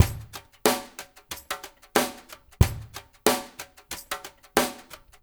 CVS DRUMS -R.wav